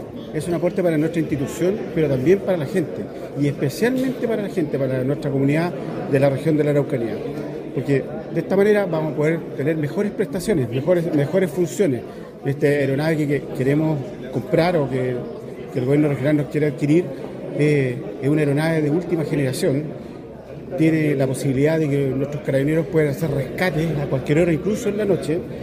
En tanto, el general Cristian Mancilla, jefe de Zona Control de Orden Público (COP), sostuvo que están muy contentos con este aporte del Gobierno Regional de La Araucanía.